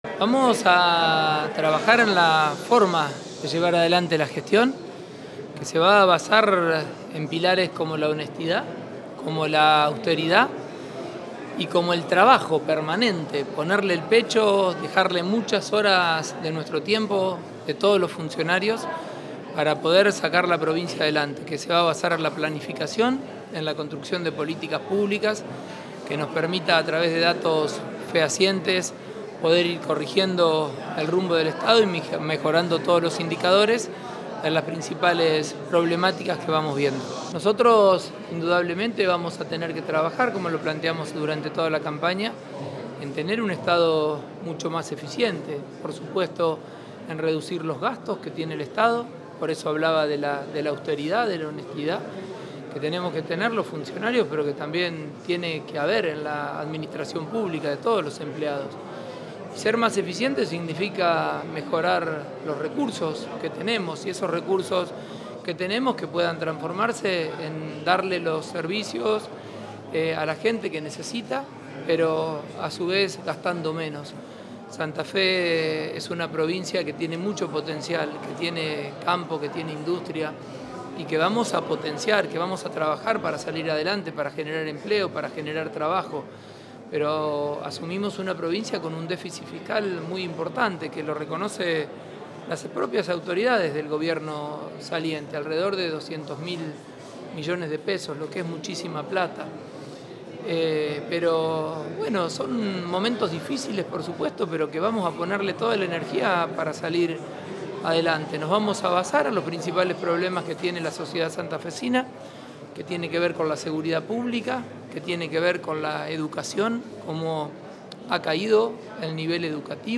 Pullaro-Scaglia.-Reunión-interministerial-.mp3